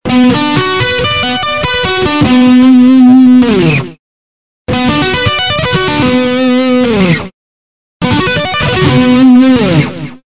• Sweeping Scale (Key: Bm)
sweepinglesson.mp3